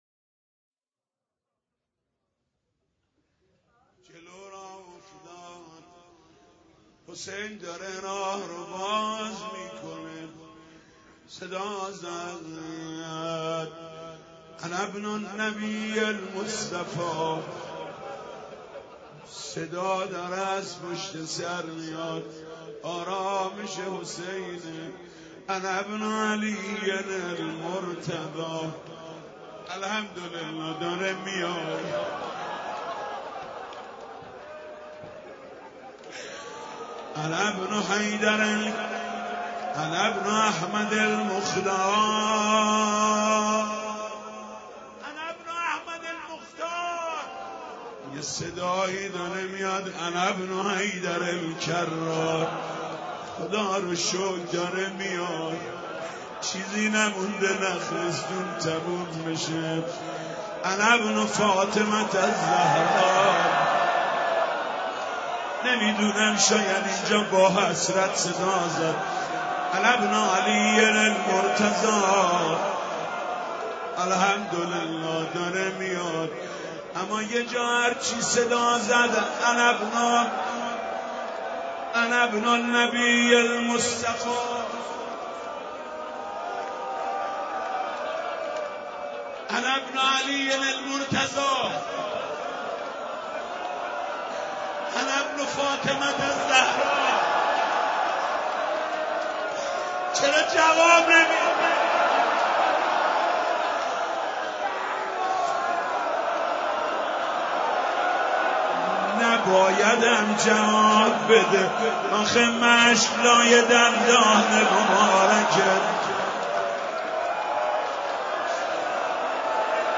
شب نهم امامزاده علی اکبر چیذر حاج محمود کریمی